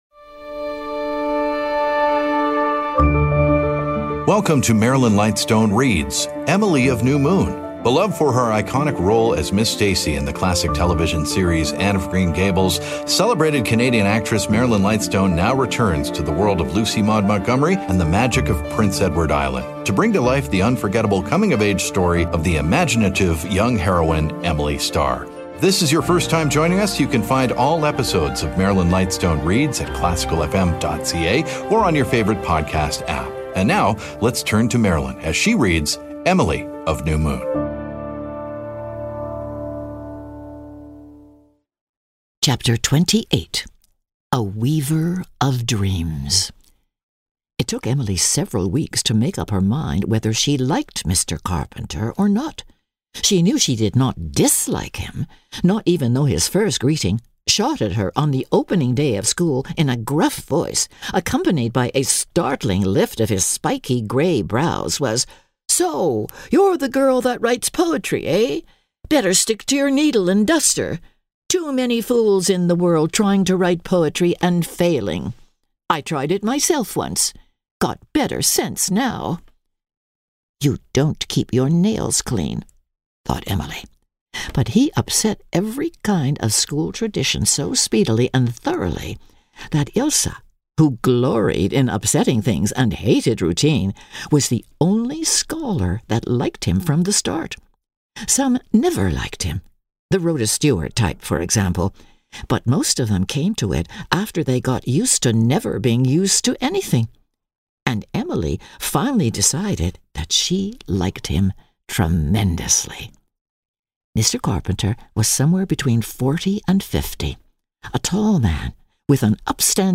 Known for her roles on stage and screen, as well as her daily radio program "Nocturne" on The New Classical FM, acclaimed actress Marilyn Lightstone now brings classic literature to life with dramatic readings.
… continue reading 339 jaksoa # Theater # Arts # Marilyn Lightstone # Zoomer Podcast Network # Society # Audio Drama # Vanity Fair